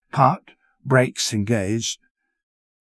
parked-brakes-engaged.wav